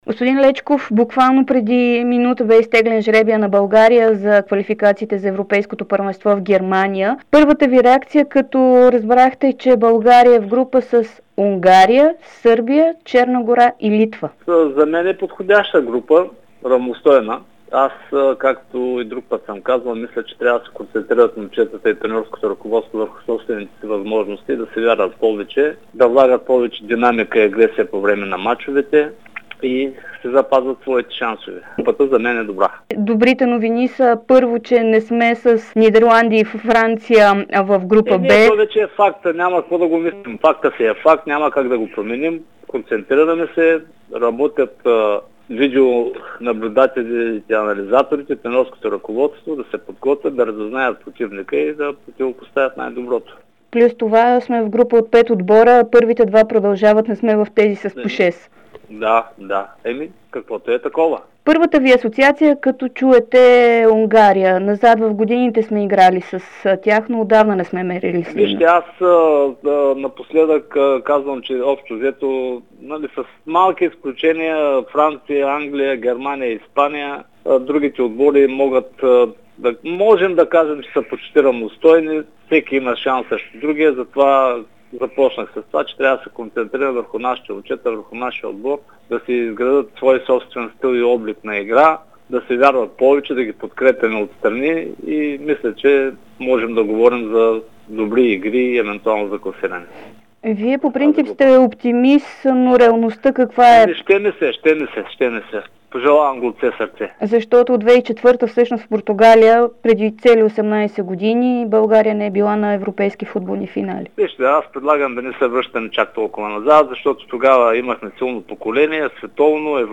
Вицепрезидентът на БФС Йордан Лечков коментира специално за Дарик и dsport жребия за европейските квалификации. България е в група със Сърбия, Унгария, Черна гора и Литва.